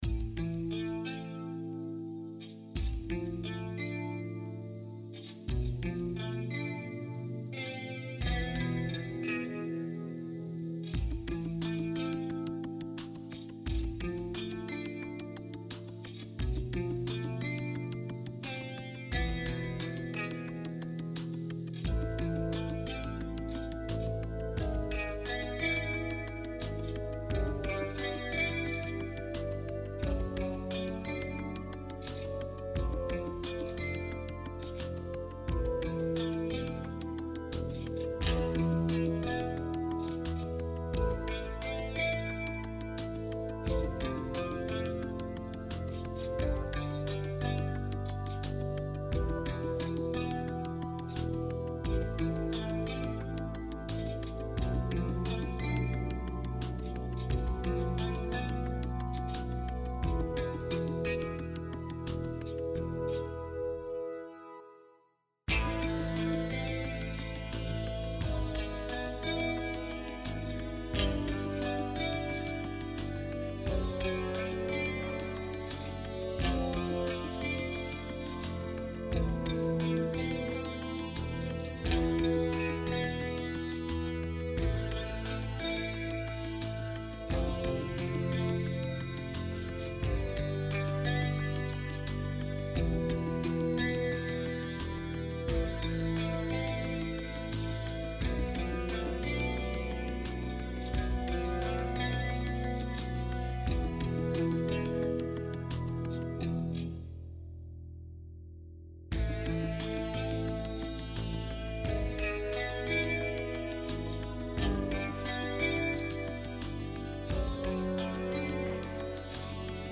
Underscore
Reduced arrangement